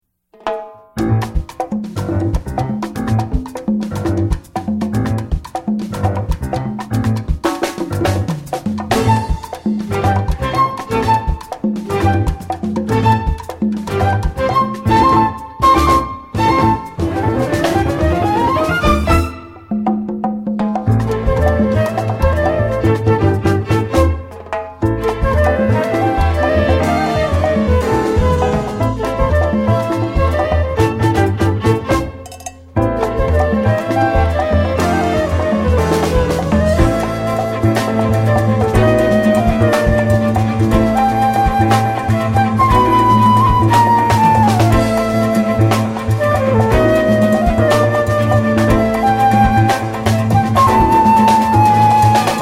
Guaguanco - Latin